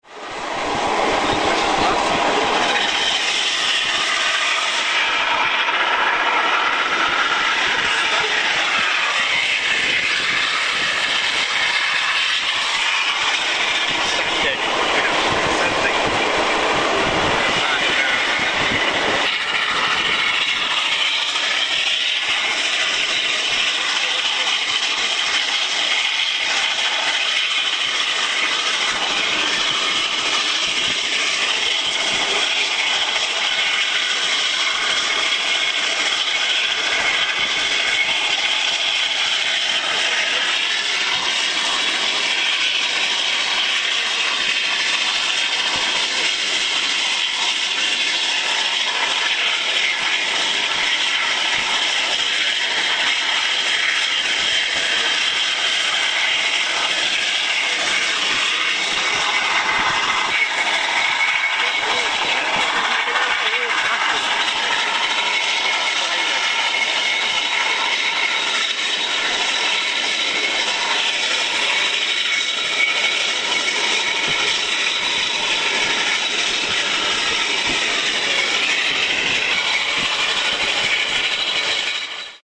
These audio files, taken from video recorded on 'Le Mistral' will give some impression.
Climbing in the Alps, edited together. Starts with "thrash" as the tubes are sanded. (976KB)
alps.mp3